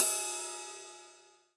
S_ride1_1.wav